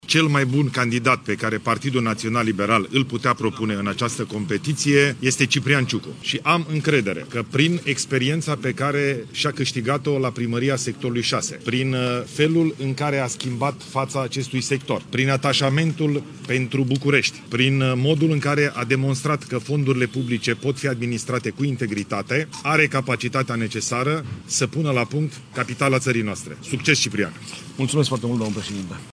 Premierul Ilei Bolojan i-a urat succes lui Ciprian Ciucu în cursa electorală.